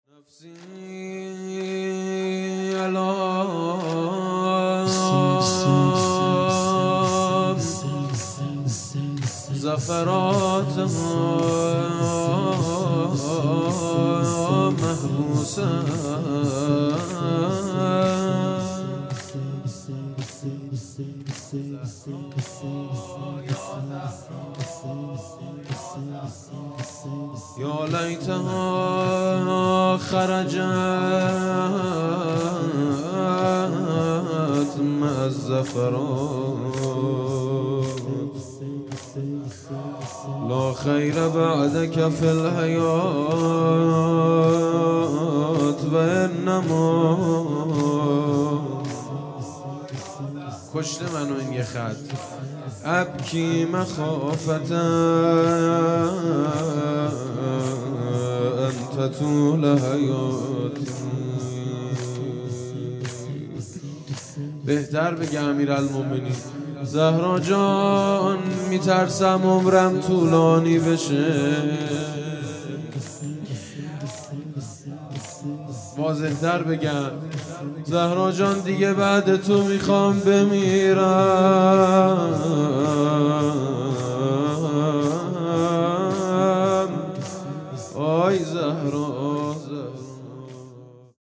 شهادت حضرت زهرا (س) | ۲۱ بهمن ۱۳۹۷